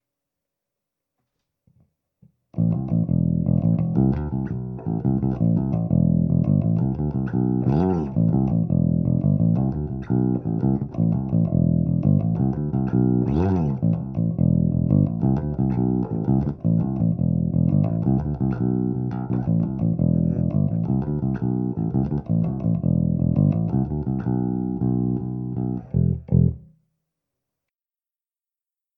Signalweg Maruszczyk Jake in Glockenklang Bass Art Classic Vorstufe an 2 FMC Pro2 112M + H Boxen mit Mikrofon abgenommen in DAW. Ist meine neutralste Anlage, d. h. das Basssignal ist so pur und unbehandelt. Eine Aufnahme ist der Splitcoil passiv mit offener Blende, die 2. Aufnahme der Humbucker aktiv mit wenig Bass- + wenig Höhenanhebung.